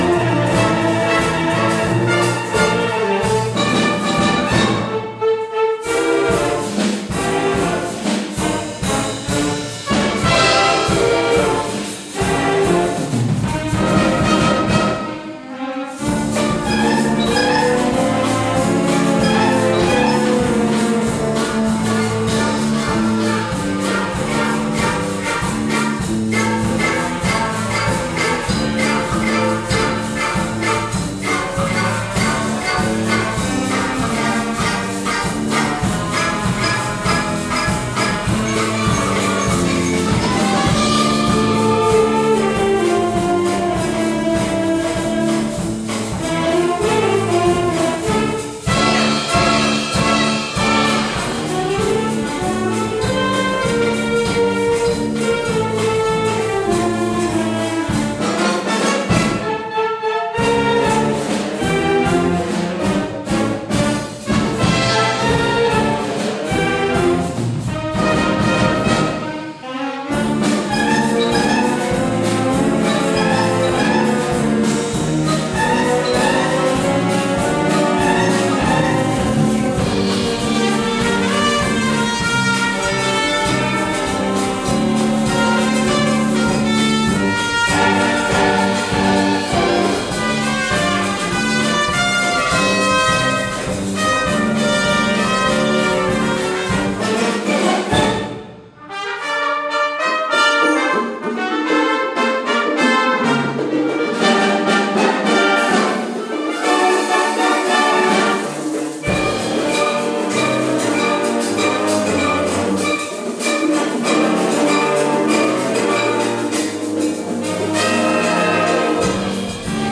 concert and sax